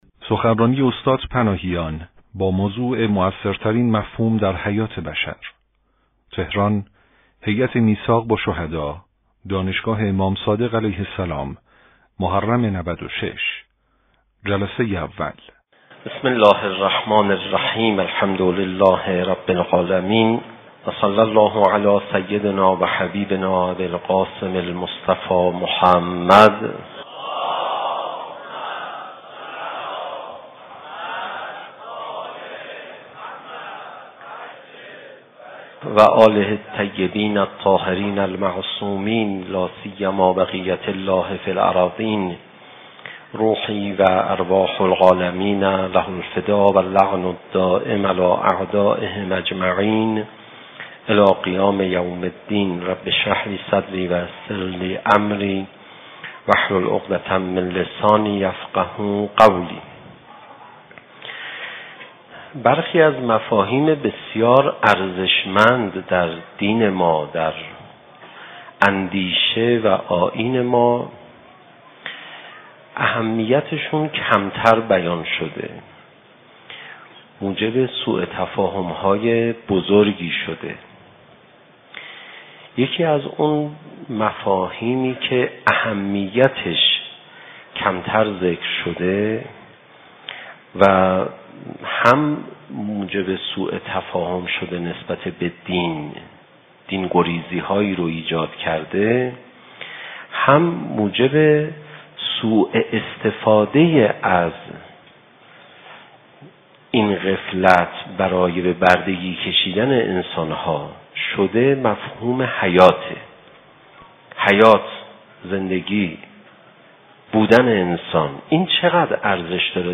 شب اول محرم 96 - دانشگاه امام صادق علیه السلام